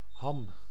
Ääntäminen
Ääntäminen : IPA: [ɦɑm] Haettu sana löytyi näillä lähdekielillä: hollanti Käännös Ääninäyte Substantiivit 1. ham US UK Suku: m .